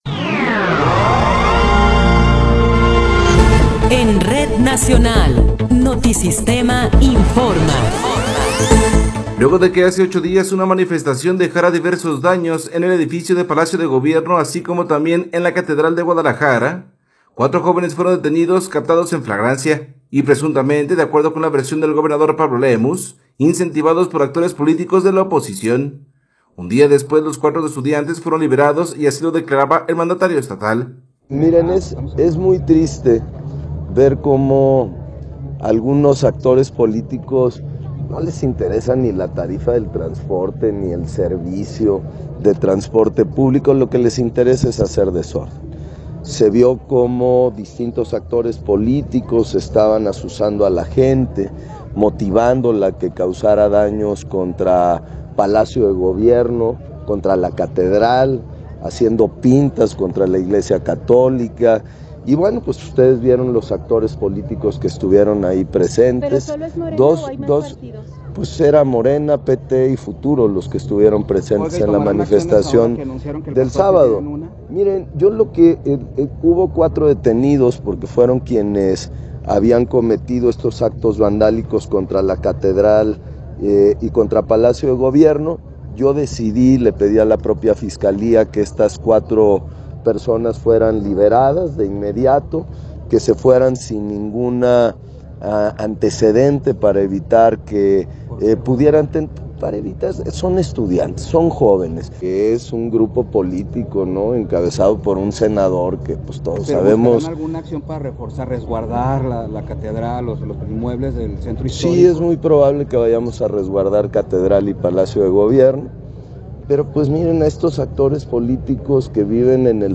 Noticiero 12 hrs. – 18 de Enero de 2026
Resumen informativo Notisistema, la mejor y más completa información cada hora en la hora.